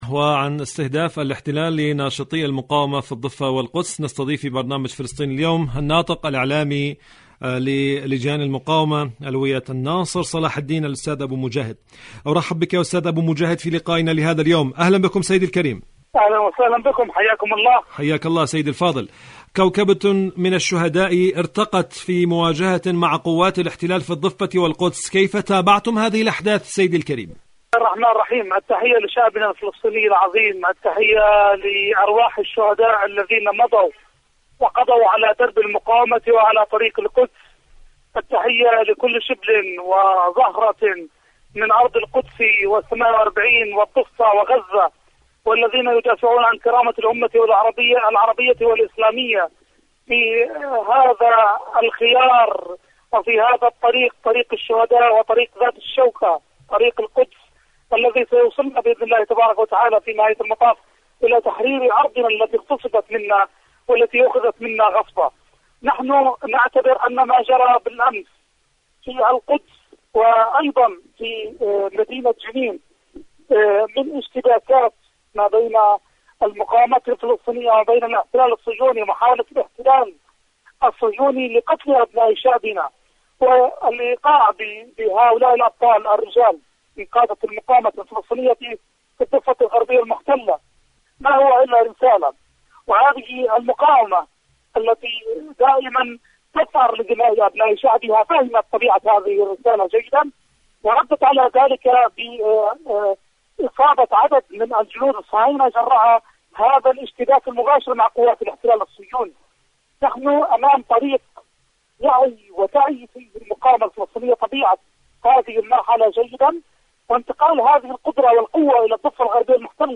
مقابلة
إذاعة طهران-فلسطين اليوم: مقابلة إذاعية